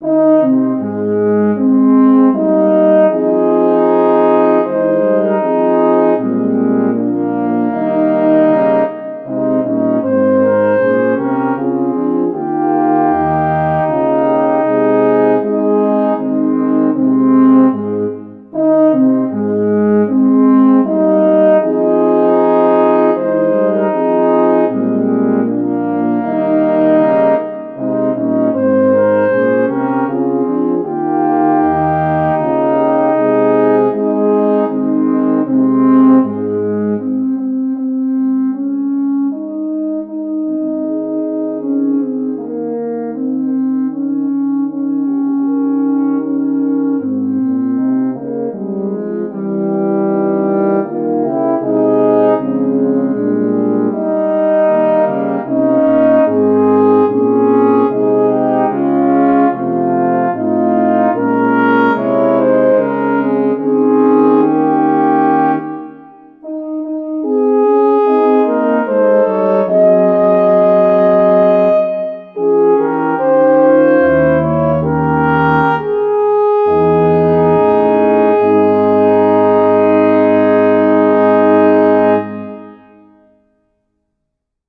Voicing: French Horn 4